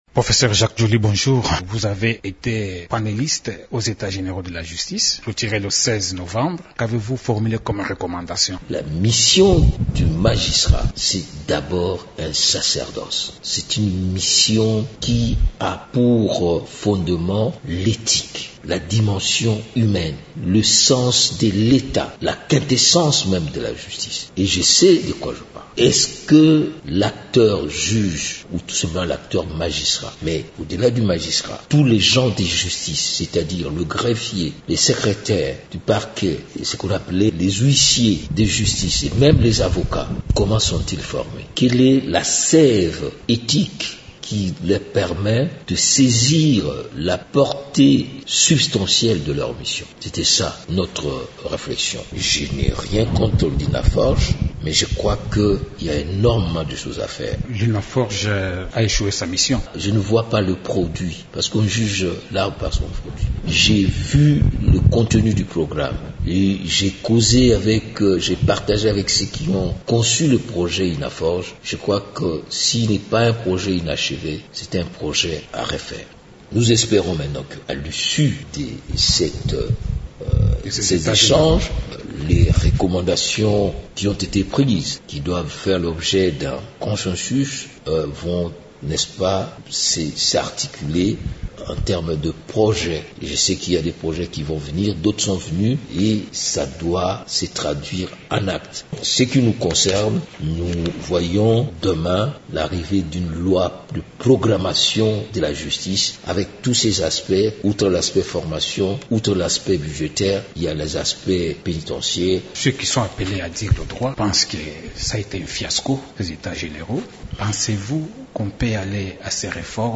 Dans cet entretien , Jacques Djoli revient en profondeur sur ce forum national et la loi de programmation de la justice qui, selon lui, devra figurer parmi les matières prioritaires de la session parlementaire du mois de mars.